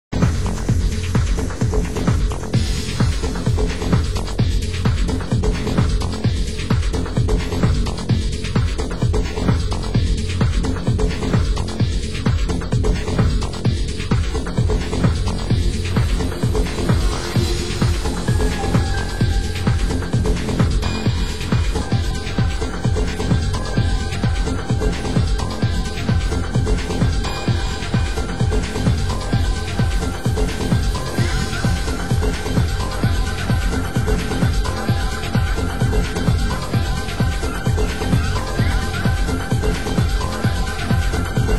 Genre: Progressive